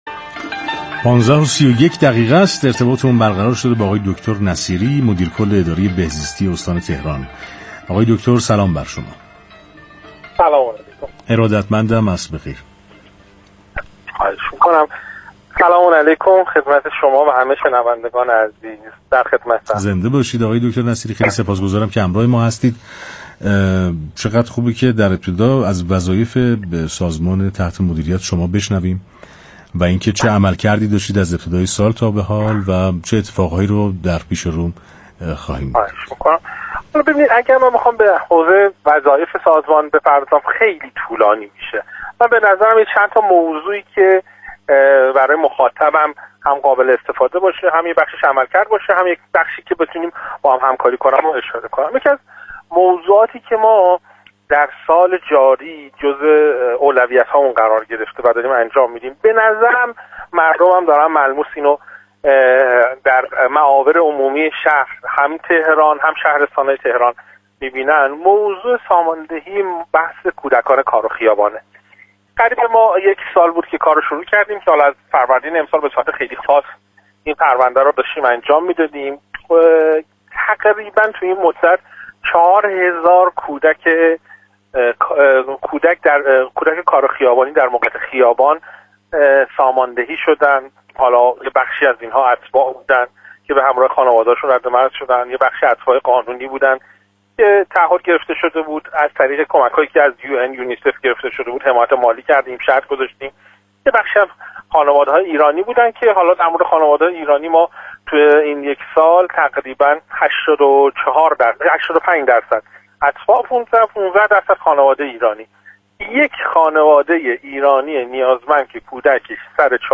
گفت‌وگوی اختصاصی رادیو تهران با مدیرکل بهزیستی استان تهران
در گفت‌وگوی زنده رادیو تهران با دکتر محمد نصیری، مدیرکل بهزیستی استان تهران، به تشریح عملکرد این نهاد در حوزه های مختلف سلامت اجتماعی از جمله مواجهه با کودکان در وضعیت مخاطره سخن گفت و از شهروندان خواست با تماس با خط ۱۲۳، در این مسیر یاریگر سازمان باشند.